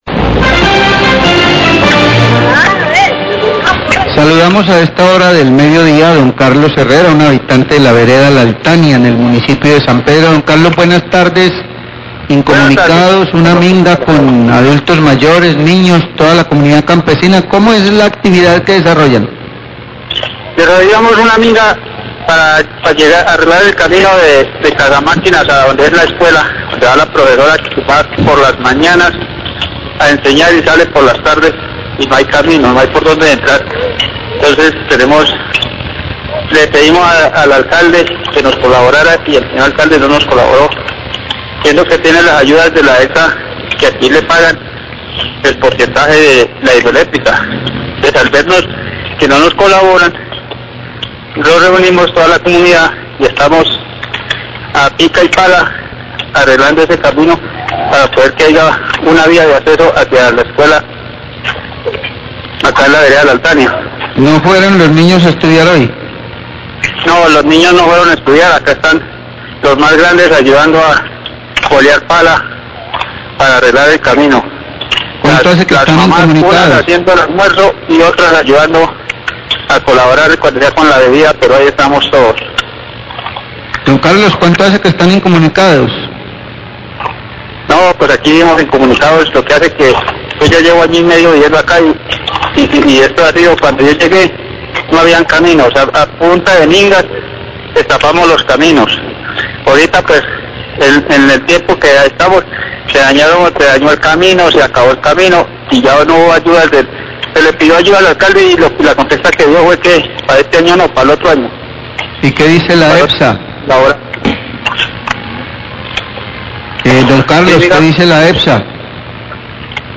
Radio
queja oyente